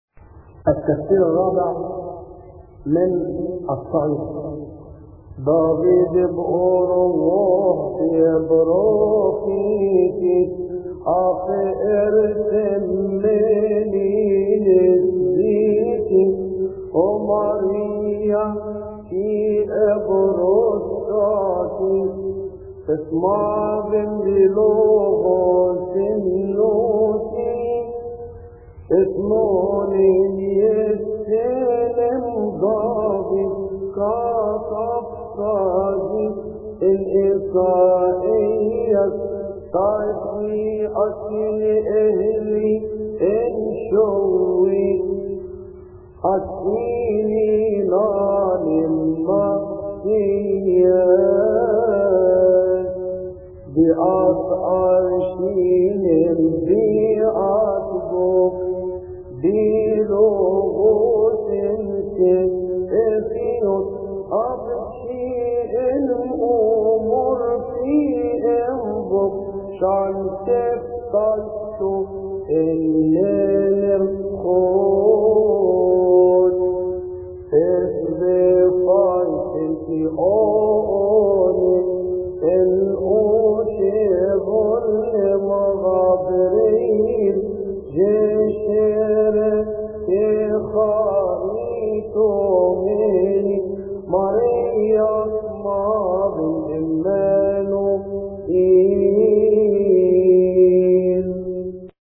التفسير الرابع من الصعيدي لثيؤطوكية السبت يصلي في تسبحة عشية أحاد شهر كيهك